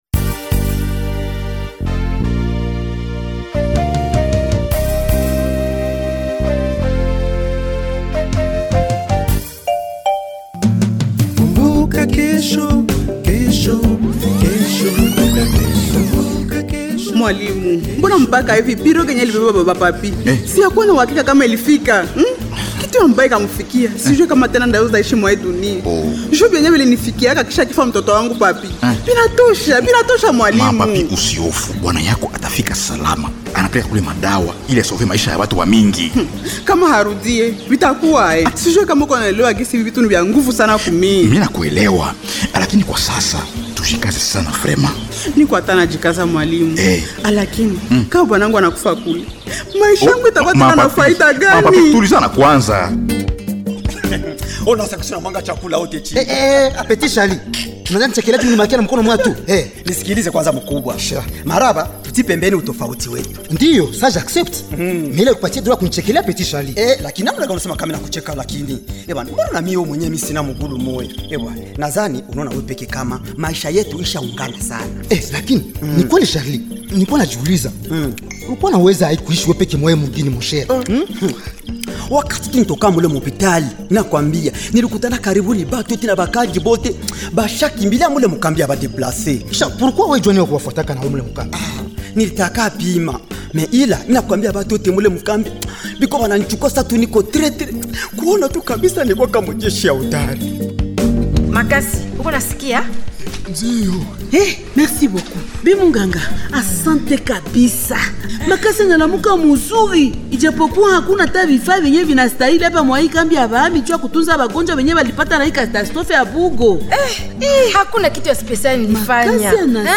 Voici le feuilleton Kumbuka Kesho du 05 au 10 janvier 2026